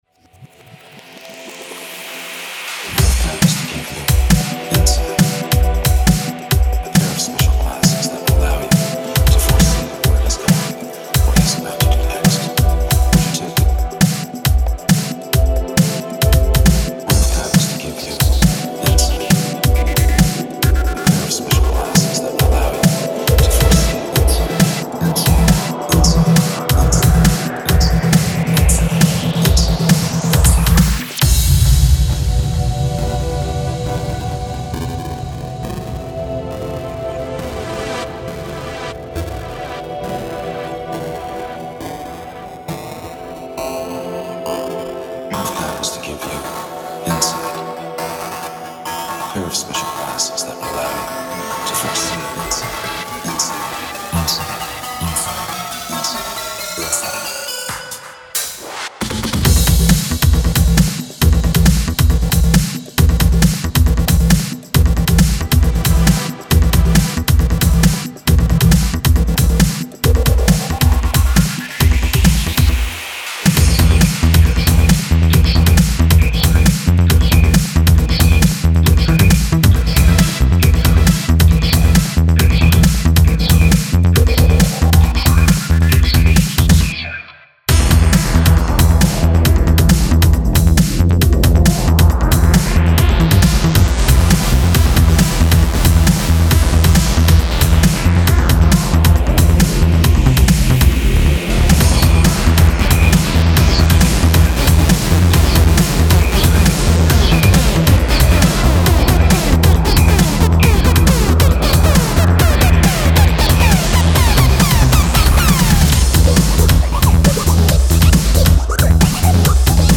Genres Breaks